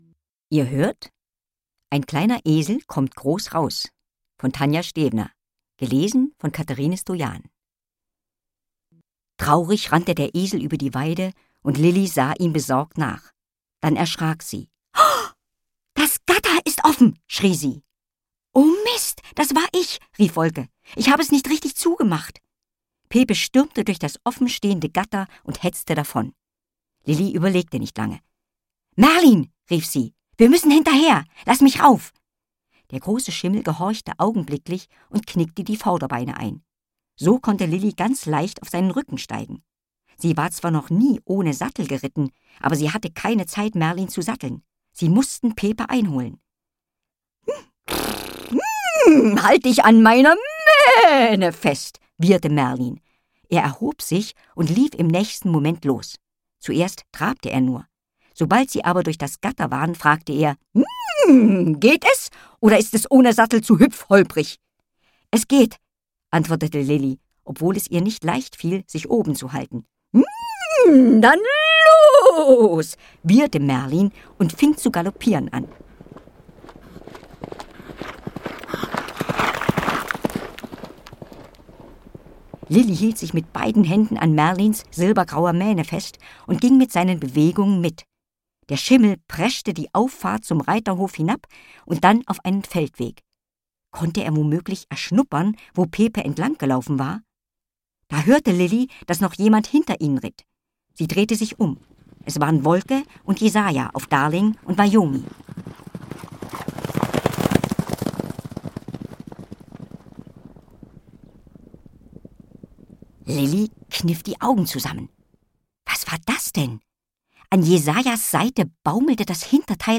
Das besondere Konzept macht diese Hörbücher zu einem Genuss für kleine Hörer: Die Geschichten sind kurz und knackig, und Musik und viele Geräusche machen das Geschehen anschaulich.
Schlagworte Bonsai • Hörbuch; Lesung für Kinder/Jugendliche • Jesahja • Katze • Lilli • Musik • Pferde • Tierdolmetscherin